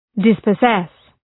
Προφορά
{,dıspə’zes}